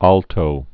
(ältō), Alvar 1898-1976.